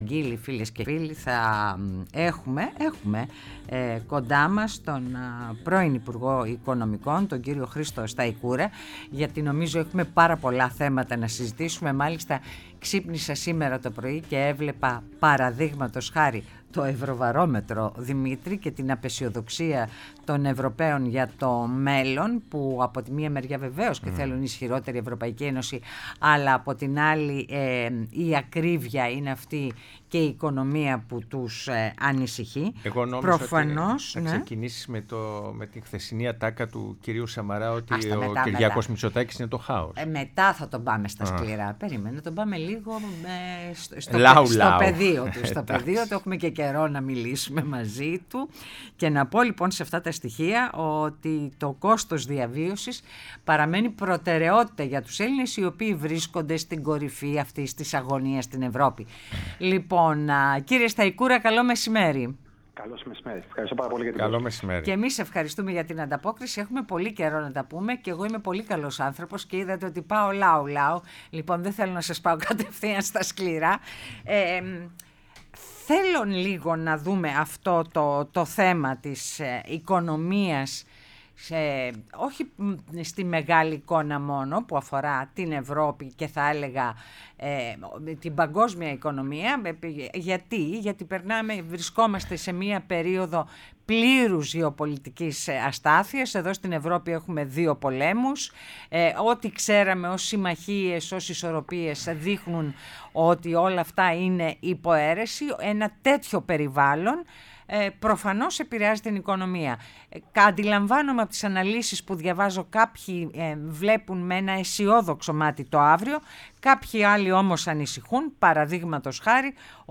Ο Χρήστος Σταϊκούρας, βουλευτής ΝΔ, πρώην υπουργός, μίλησε στην εκπομπή Ναι, μεν Αλλά»